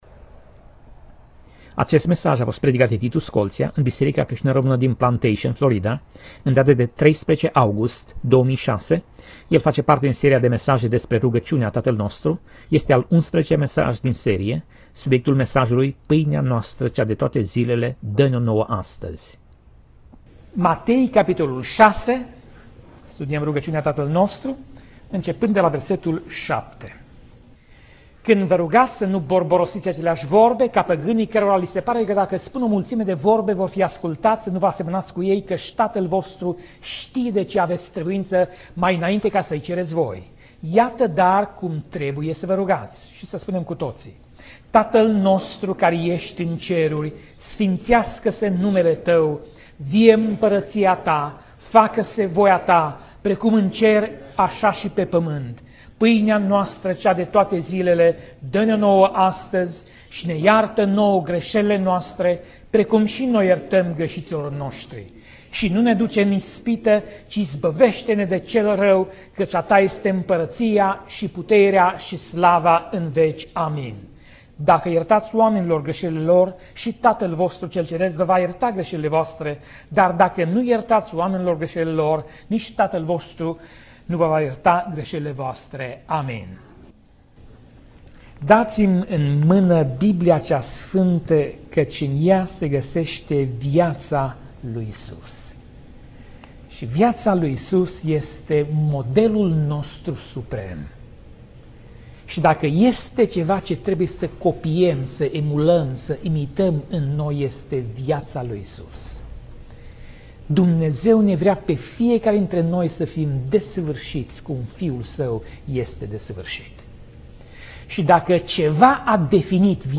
Pasaj Biblie: Matei 6:9 - Matei 6:13 Tip Mesaj: Predica